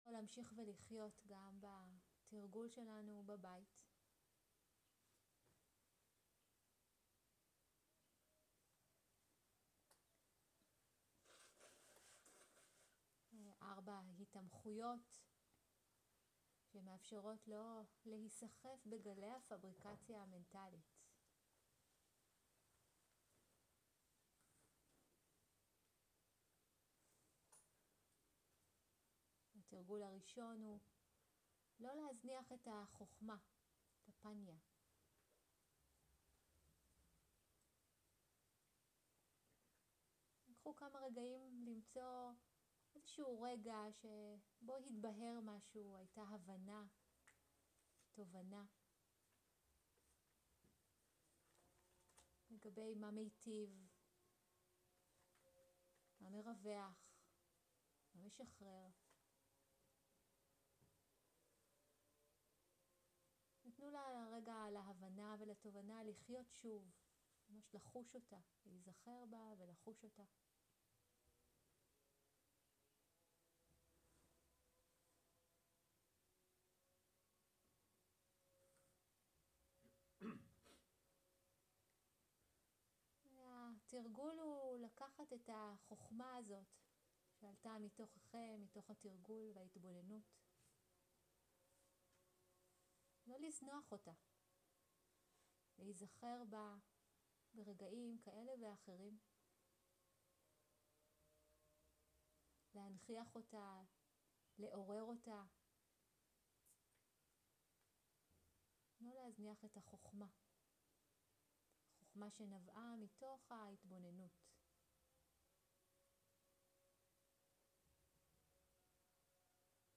סוג ההקלטה: שיחת סיכום
עברית איכות ההקלטה: איכות גבוהה מידע נוסף אודות ההקלטה